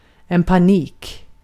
Ääntäminen
Vaihtoehtoiset kirjoitusmuodot (vanhentunut) panick (vanhentunut) panical Synonyymit panicgrass panic grass lose one's head Ääntäminen UK : IPA : /ˈpæn.ɪk/ US : IPA : [ˈpæn.ɪk] Tuntematon aksentti: IPA : /ˈpanɪk/